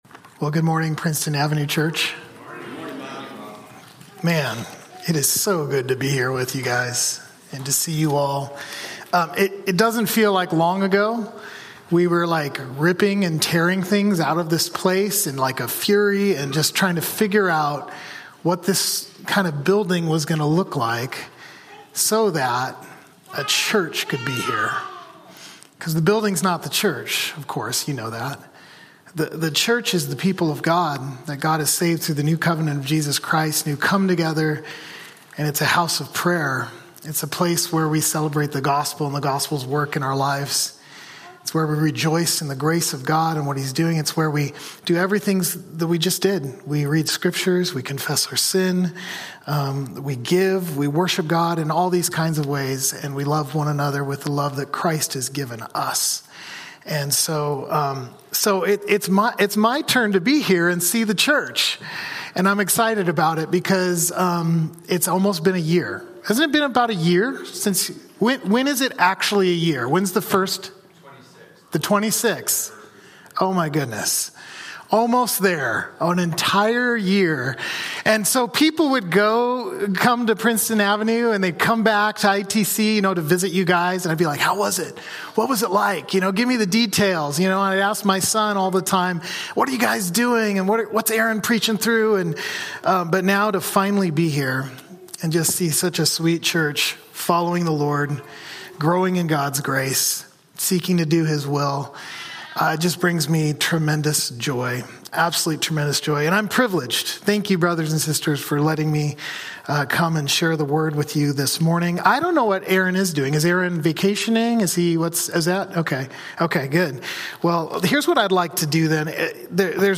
About Connect Events Groups Church Center GriefShare Sermons Articles Give The Book of Ruth October 12, 2025